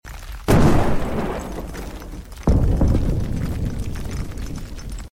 How Yellowstone's Volcano might erupt sound effects free download
How Yellowstone's Volcano might erupt - AI simulation